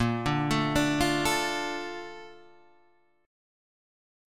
A# Major 7th